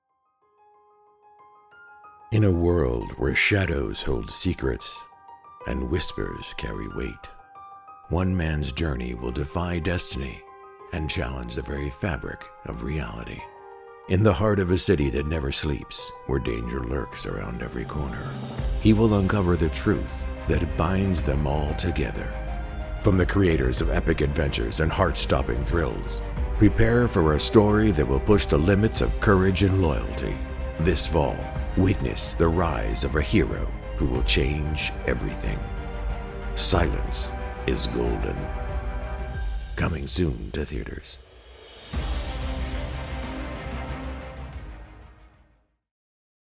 Professional Voice Artist
EPIC Movie Trailer
English - USA and Canada
Middle Aged
Senior